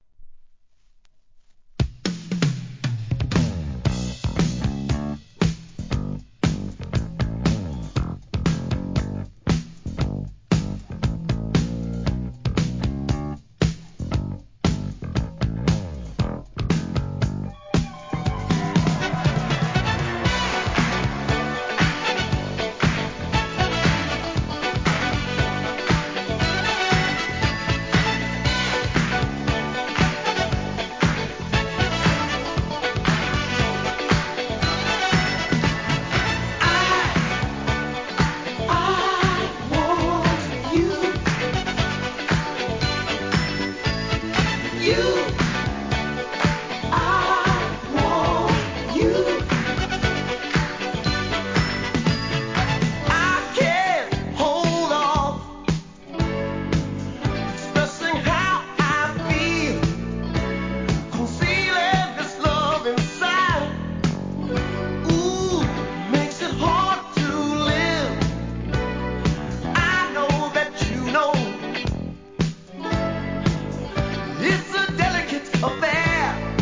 SOUL/FUNK/etc... 店舗 ただいま品切れ中です お気に入りに追加 1980年、人気のブギー名曲!